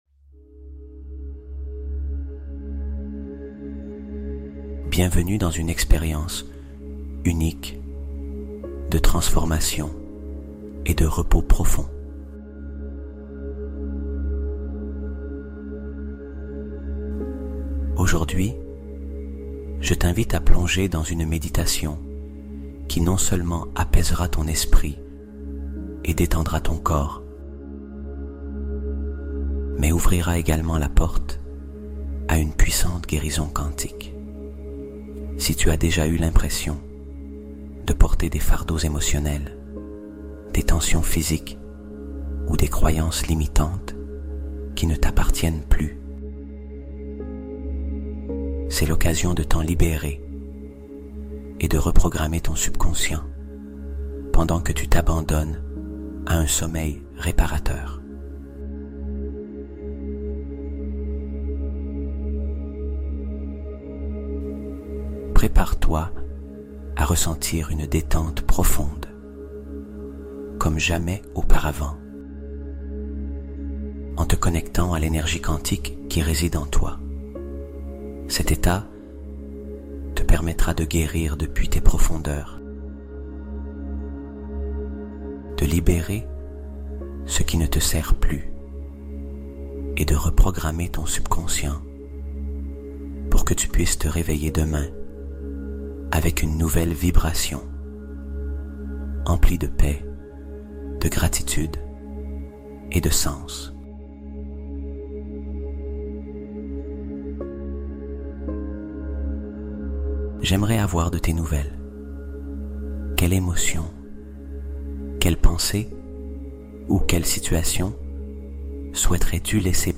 Guérison Quantique Totale : Méditation + Hypnose Pour Reprogrammer Ton Subconscient (HD)